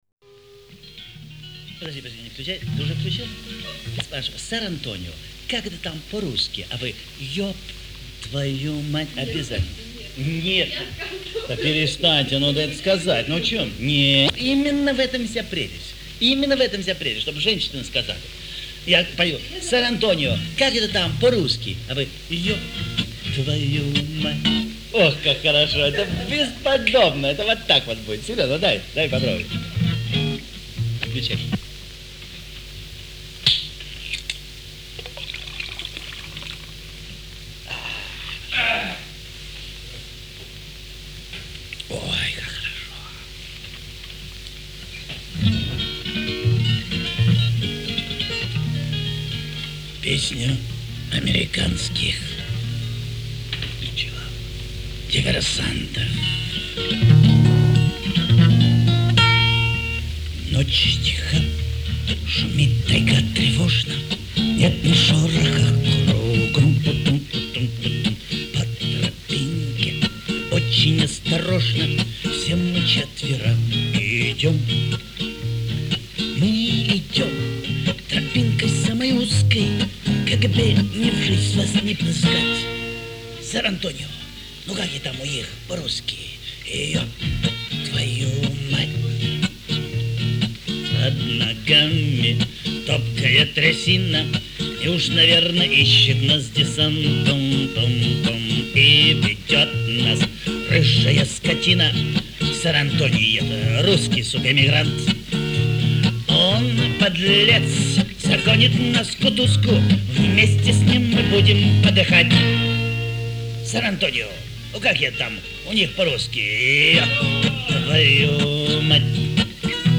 К сожалению, в таком качестве в озвучку спектакля не вставишь.